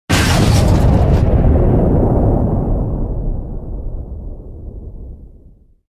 youhit3.ogg